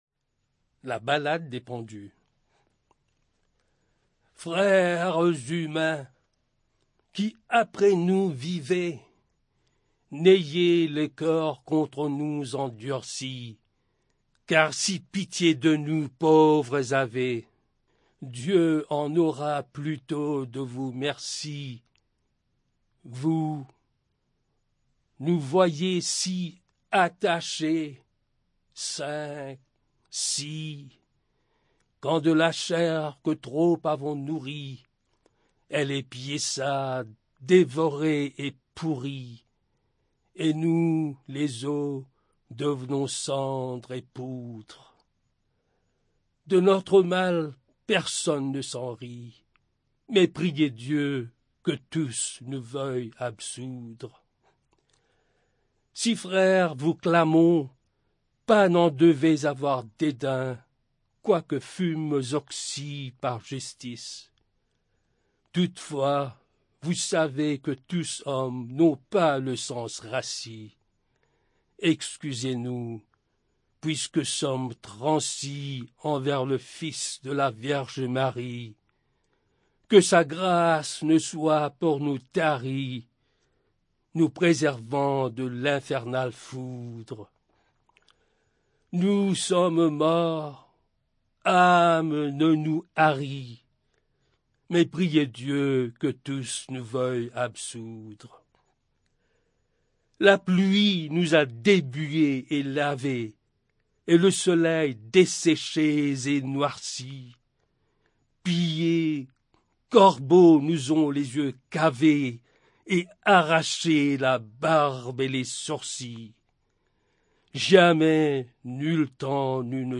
Audio non-musical
poetry